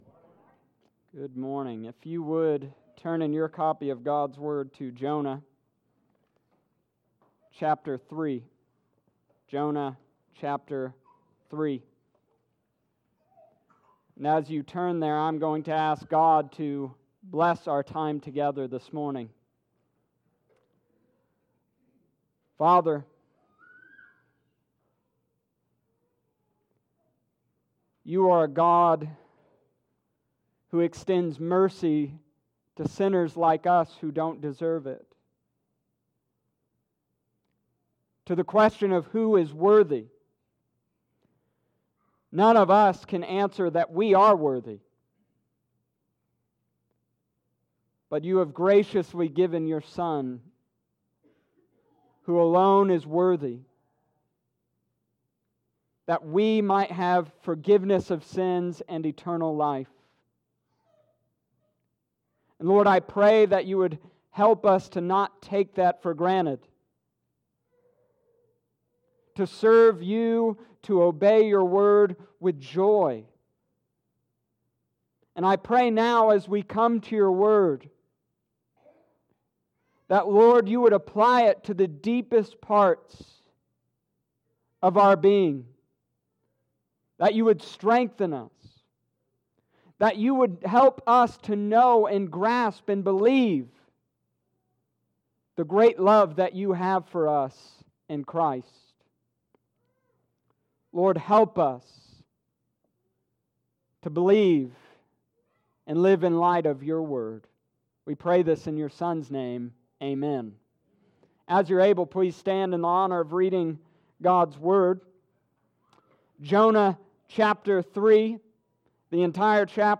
Summary of Sermon: This week, Jonah and the people of Nineveh show us the proper response to God’s warnings and commands.